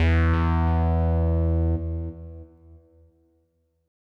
bass note03.wav